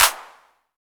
808 CLAP.wav